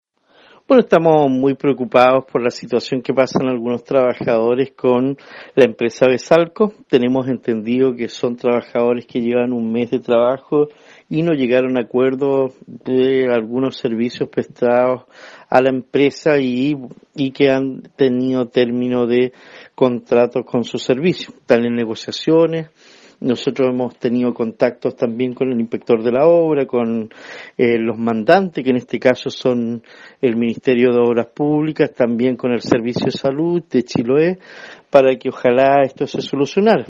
El alcalde de Quellón, Cristian Ojeda se refirió a este nuevo inconveniente que se produce entre privados y que afecta a la comunidad.